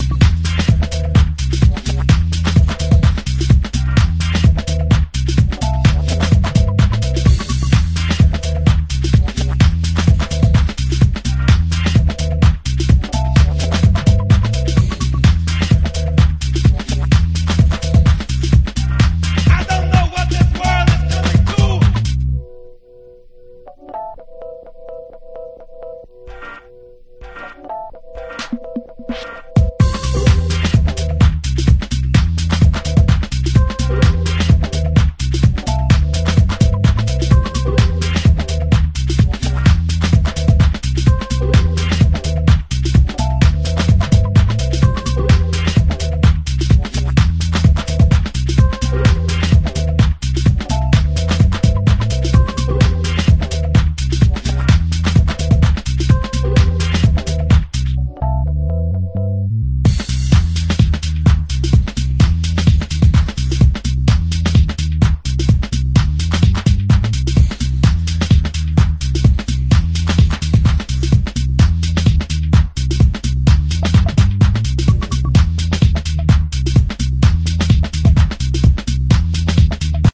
Long building deep dubby stompin' acid house.